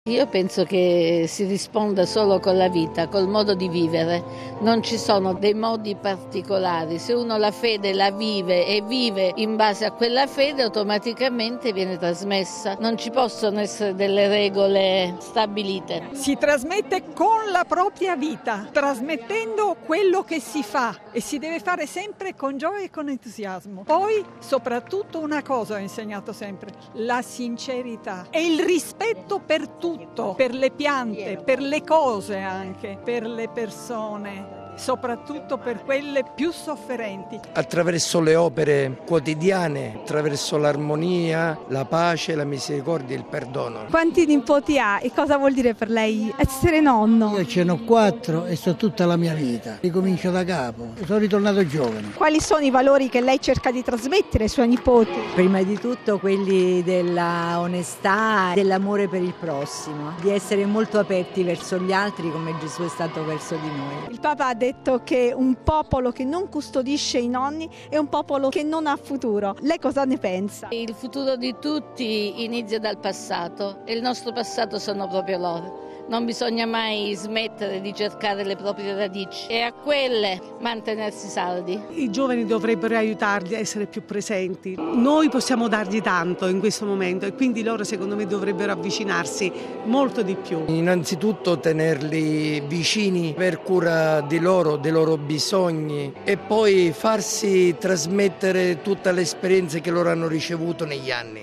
Decine di migliaia i nonni e gli anziani giunti da tutto il mondo in Piazza San Pietro per incontrare Papa Francesco, che li ha invitati a trasmettere la fede soprattutto alle nuove generazioni. Ascoltiamo alcuni commenti raccolti